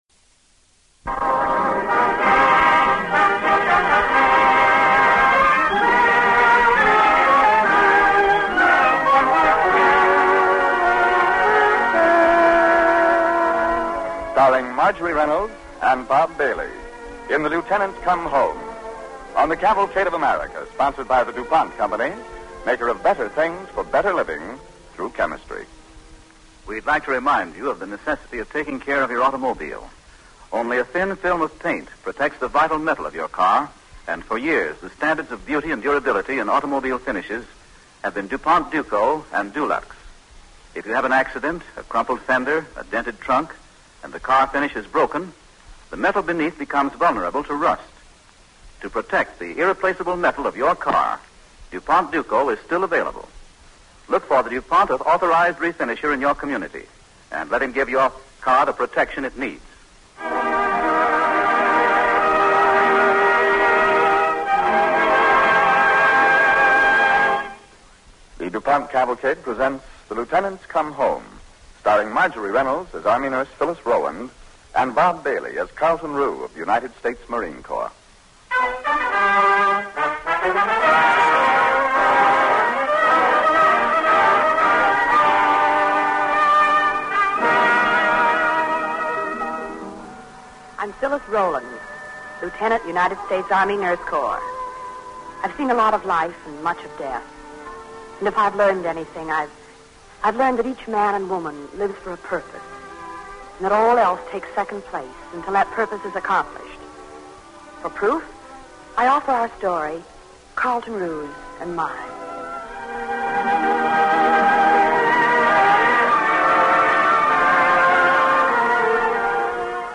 starring Marjorie Reynolds and Bob Bailey
Cavalcade of America Radio Program